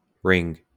wymowa:
enPR: rĭng, IPA/ɹɪŋ/, SAMPA/rIN/
homofon: ring